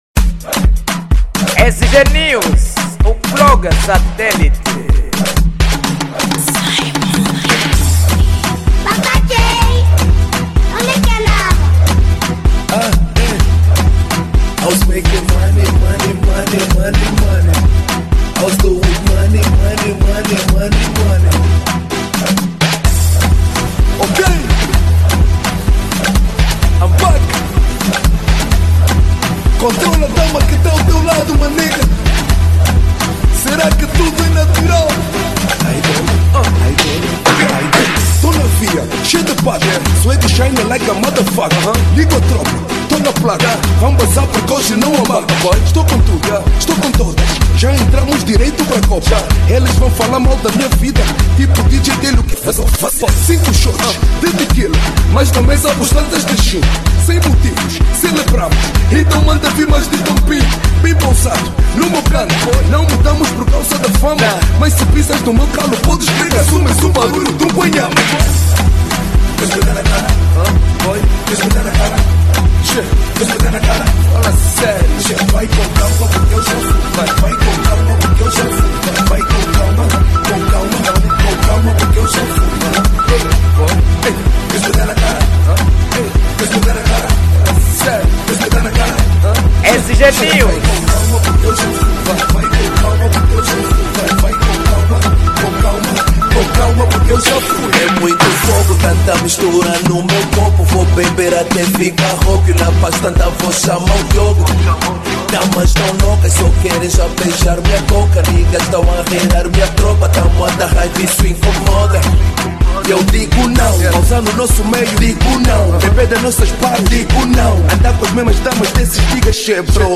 Género : Afro House